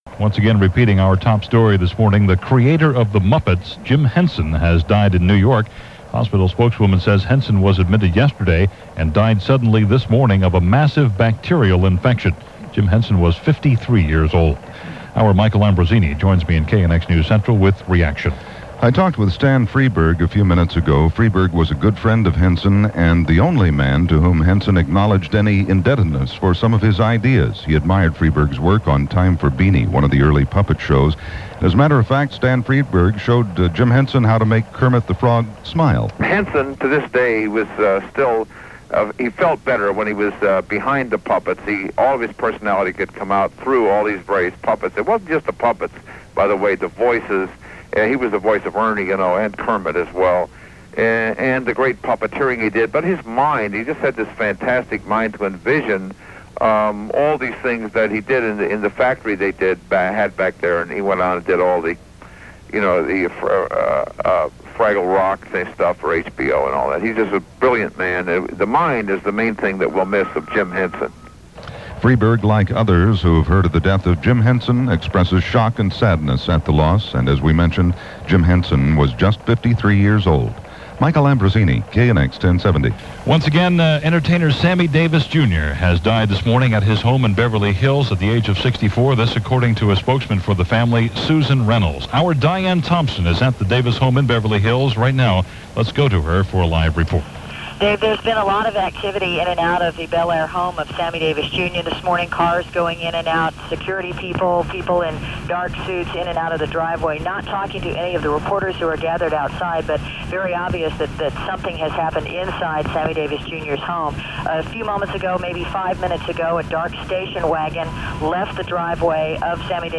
May 16, 1990 – CBS Radio News – KNX, Los Angeles – Bulletins, reports and news – Gordon Skene Sound Collection –